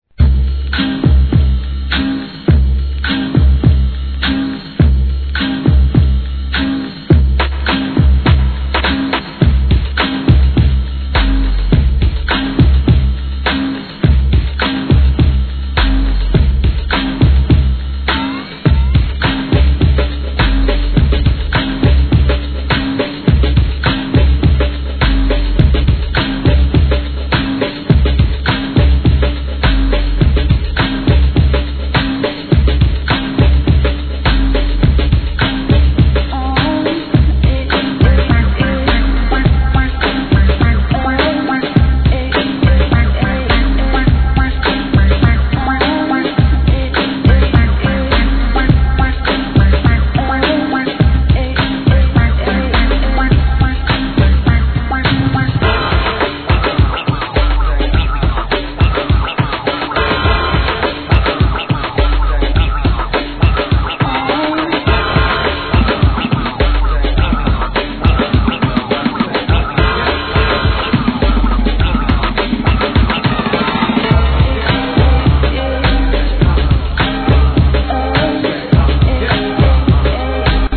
HIP HOP/R&B
オークランド発アンダーグランド!!